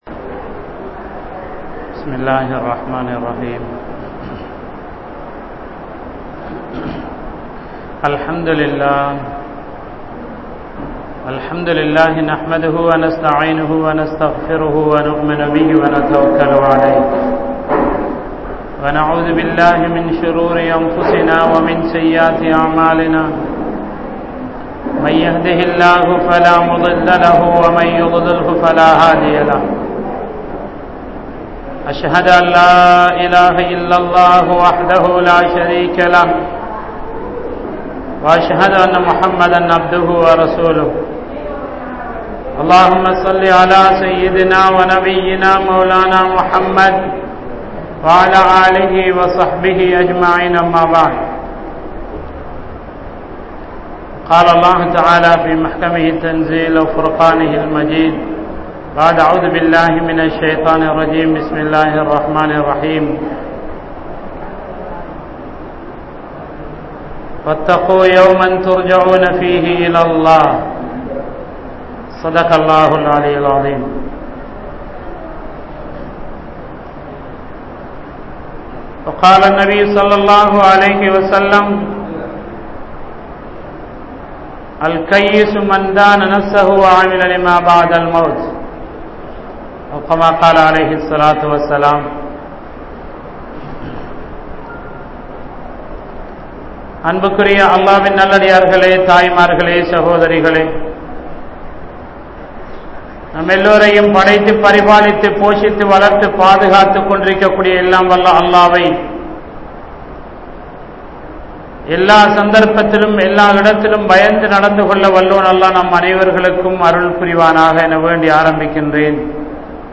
Maranathitku Neengal Thayaara? (மரணத்திற்கு நீங்கள் தயாரா?) | Audio Bayans | All Ceylon Muslim Youth Community | Addalaichenai
Masjithur Ravaha